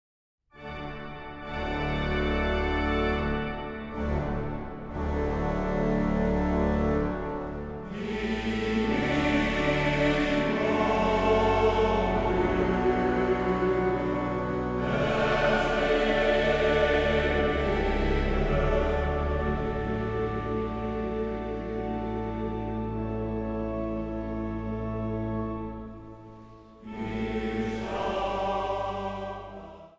orgel
panfluit
hobo.
Zang | Mannenkoor